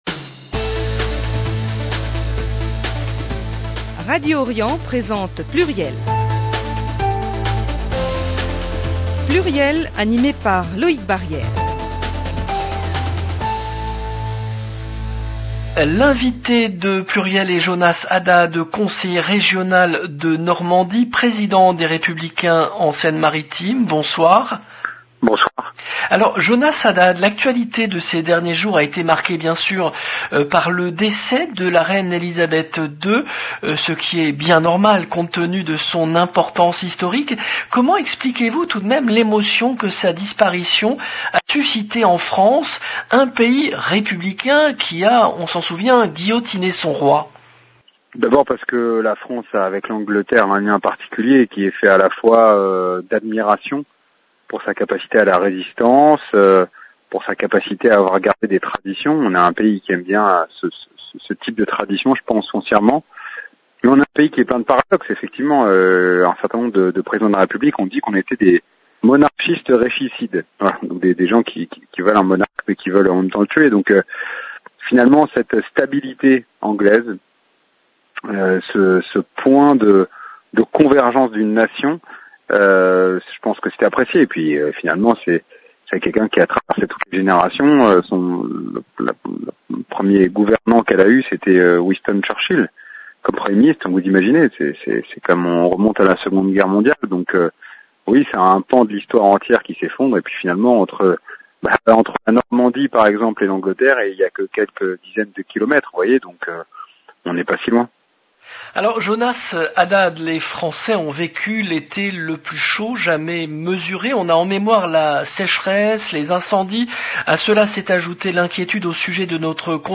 L’invité de PLURIEL est Jonas Haddad, conseiller régional de Normandie, président des Républicains de Seine-Maritime